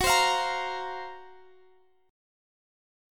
Listen to GbM7b5 strummed